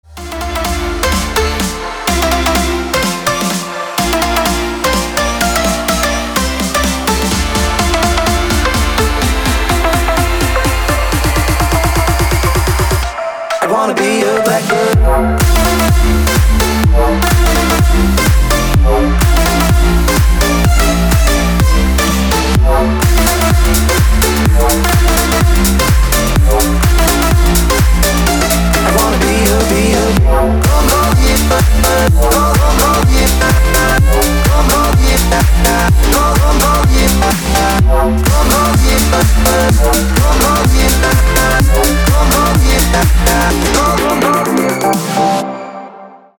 • Качество: 320, Stereo
громкие
dance
электронная музыка
club